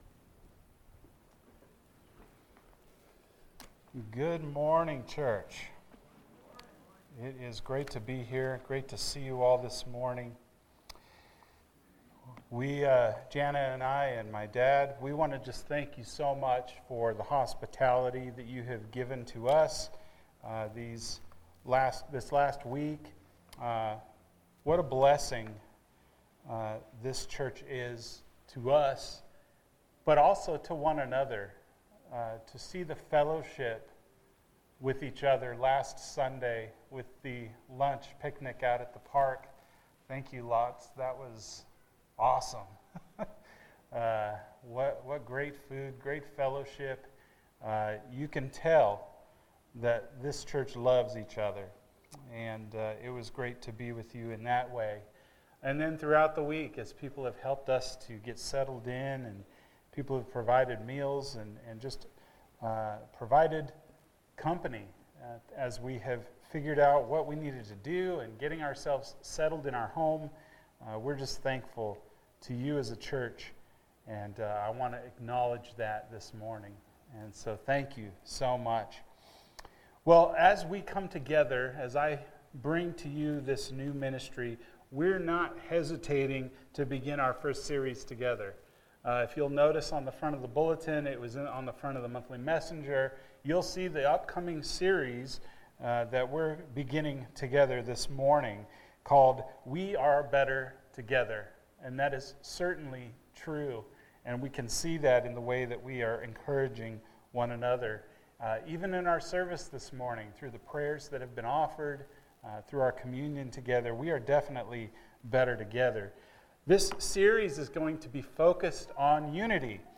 Everything In Common – Acts 2:42-47 – Sermon